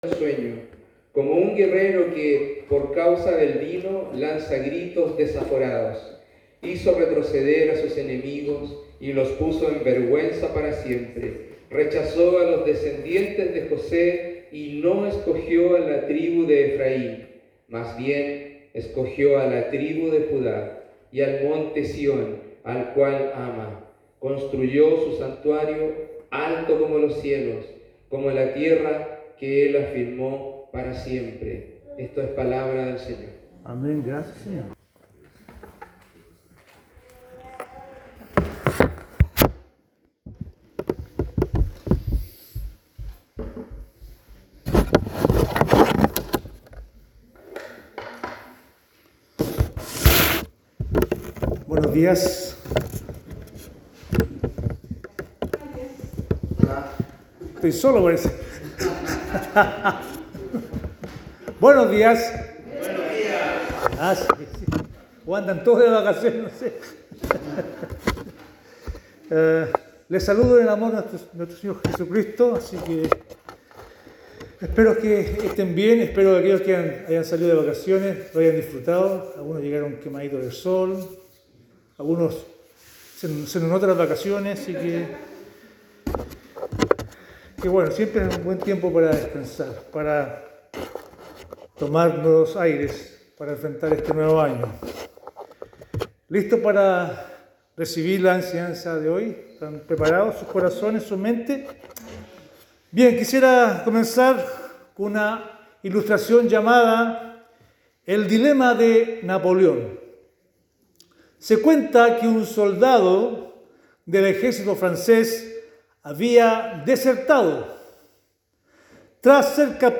Sermón sobre Salmo 78 : 65 - 69